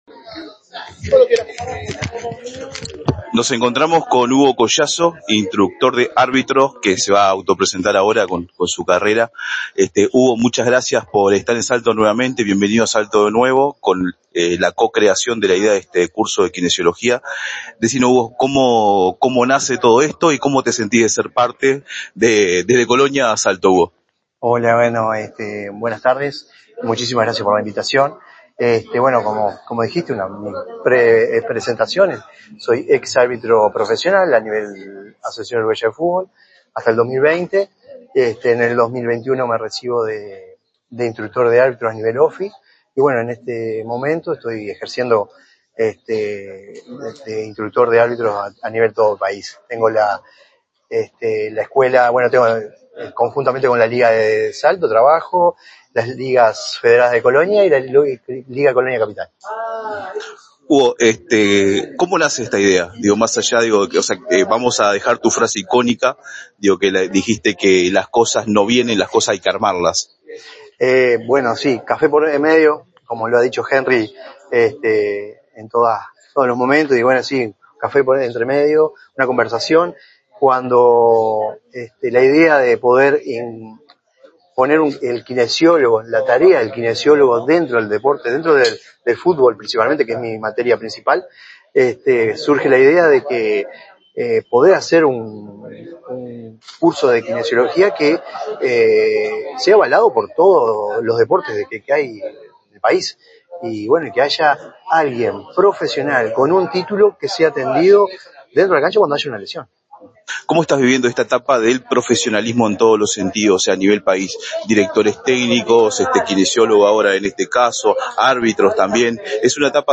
Jornada de entrega de títulos a egresados del curso del Centro Integral de Kinesiología Avanzada del Uruguay (CIKAU) en Salto, en el Aula Magna de Regional Norte.